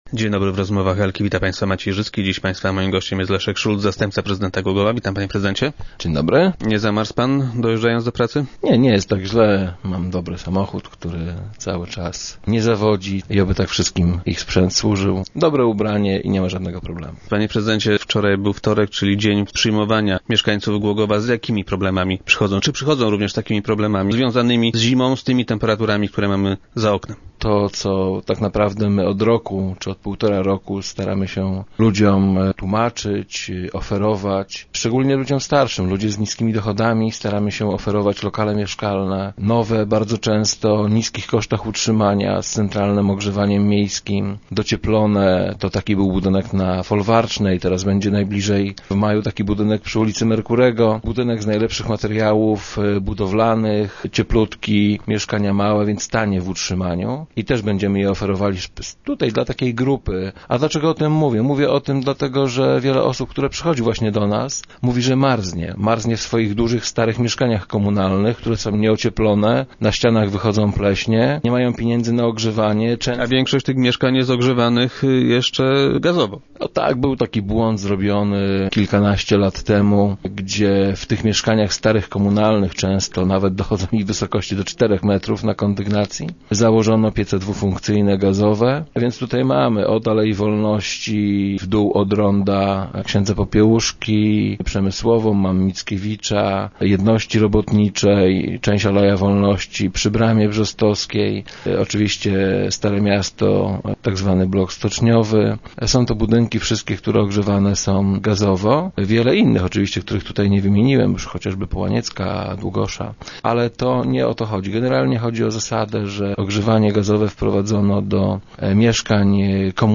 - Ogrzewanie gazowe w starych, nieocieplonych budynkach komunalnych to był błąd - twierdzi Leszek Szulc, zastępca prezydenta Głogowa.